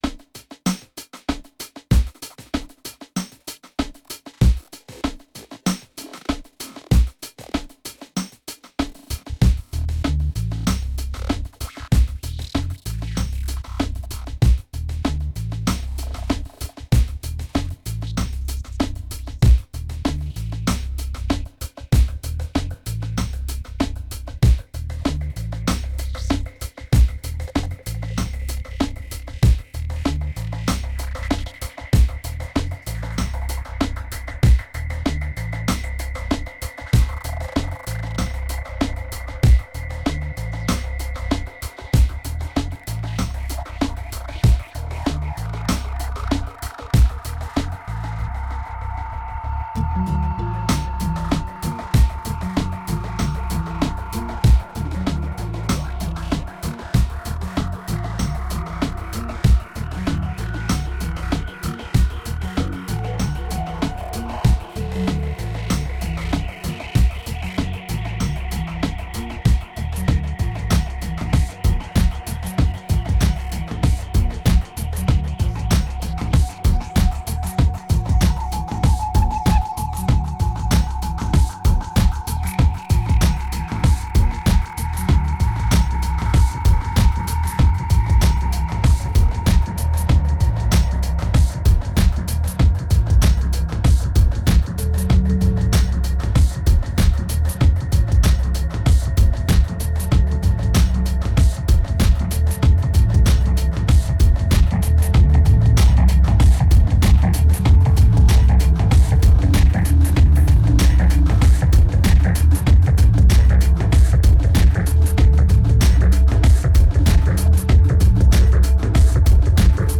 2158📈 - -51%🤔 - 96BPM🔊 - 2010-10-16📅 - -322🌟